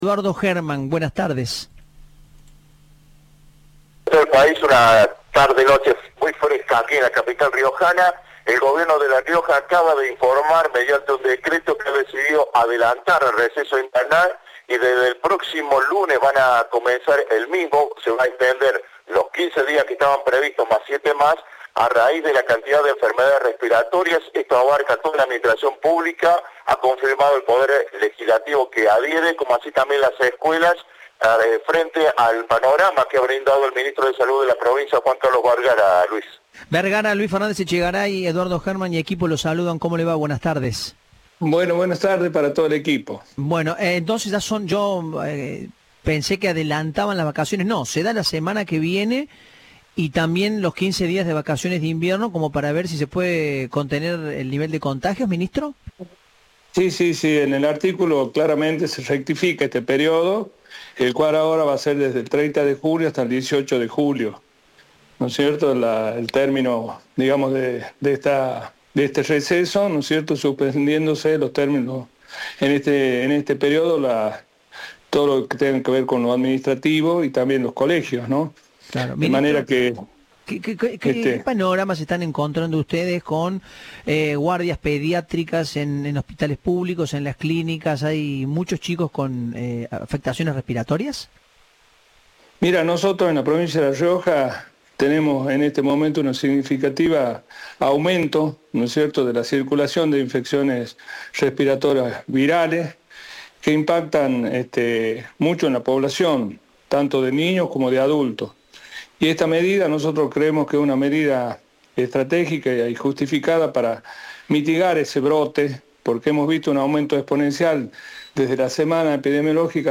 El ministro de Salud, Juan Carlos Vergara, en diálogo con Cadena 3 explicó que la decisión responde a un “significativo aumento” de infecciones respiratorias virales, que afectan tanto a niños como a adultos.